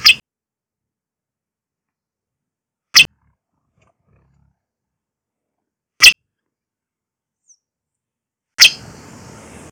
Piranga flava - Fueguero
fueguero.wav